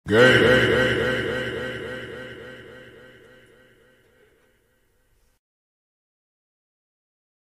G*y Echo Voice Tiktok